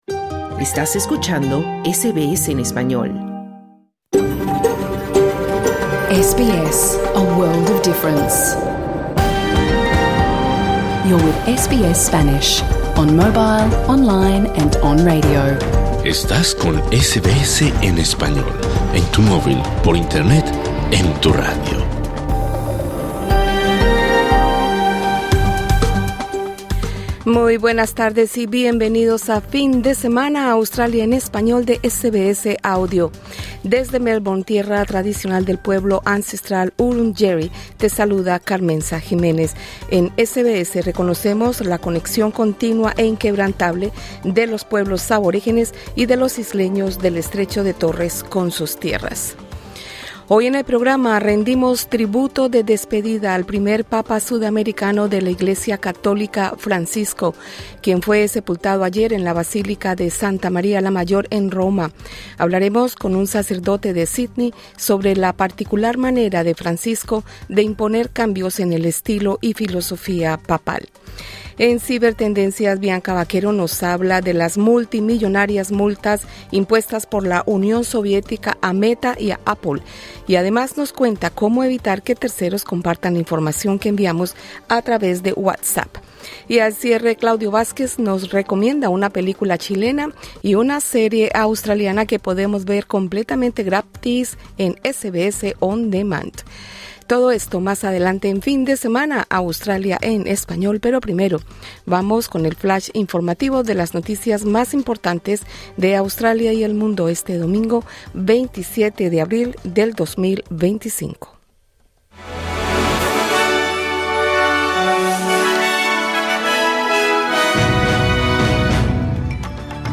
Hoy en el programa hacemos un especial homenaje de despedida al papa Francisco, quien fue sepultado ayer en una emotiva ceremonia en El Vaticano. Tenemos una entrevista